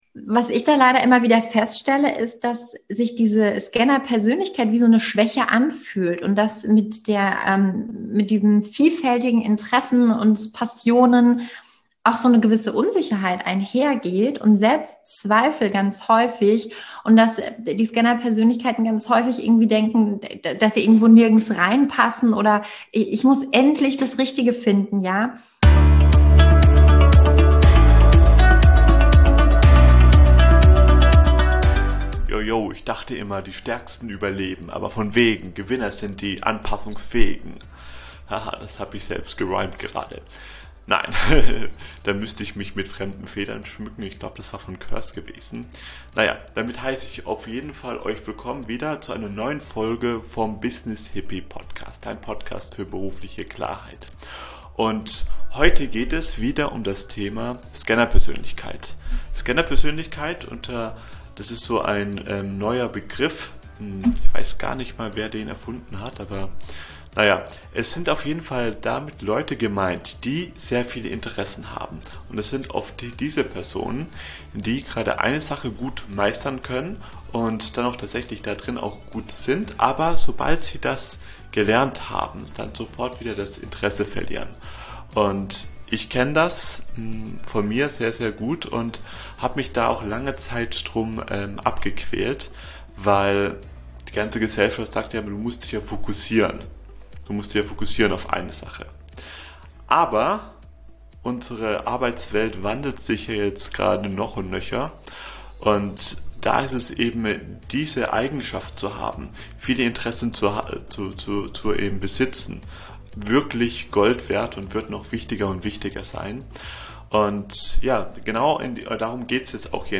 #94 So lebst du deine Vielseitigkeit als STÄRKE aus - Interview